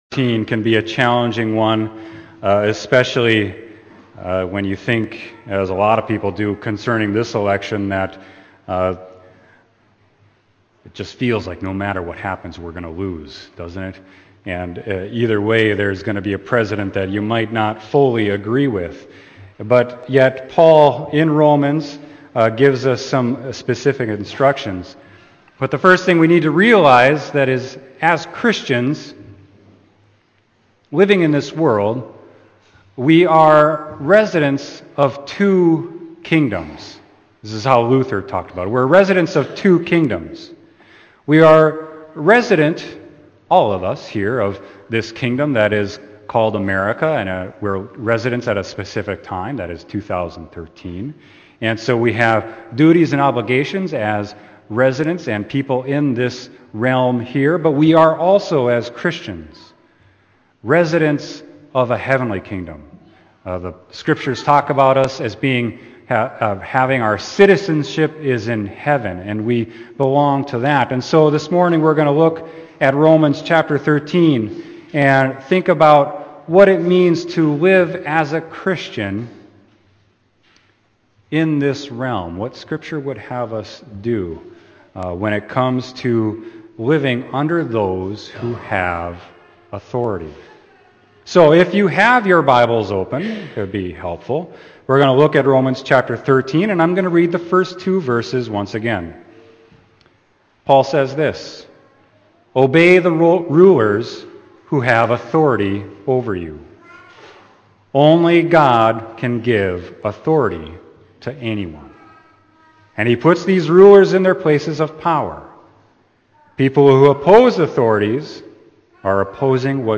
Sermon: Romans 13:1-7